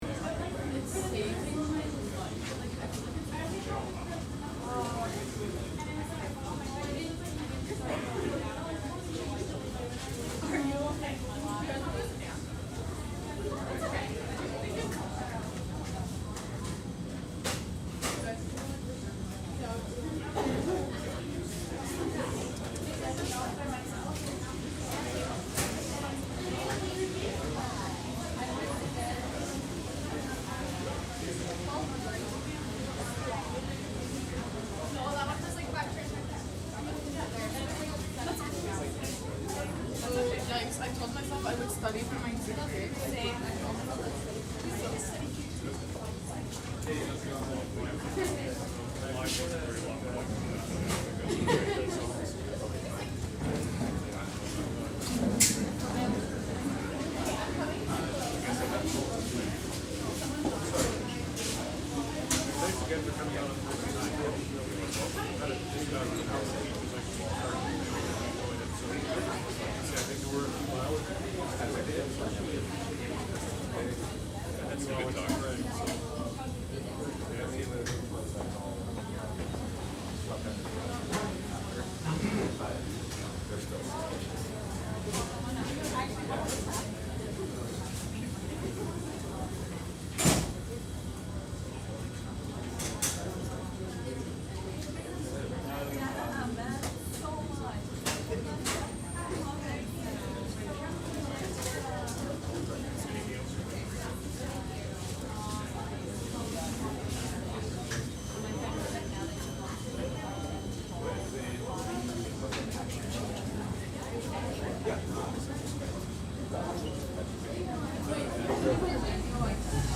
دانلود صدای محیط کافه از ساعد نیوز با لینک مستقیم و کیفیت بالا
جلوه های صوتی